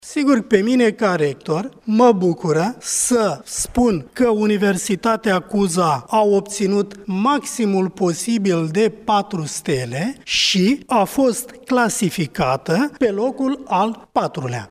Rectorul Universității Al. I Cuza din Iași, prof.dr Tudorel Toader: